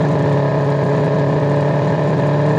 f1_02_idle2.wav